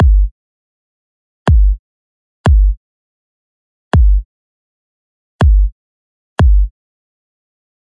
游戏声音 " 远距传输 06
Tag: 星系 游戏 瞬间移动 机器 UFO 空间 外来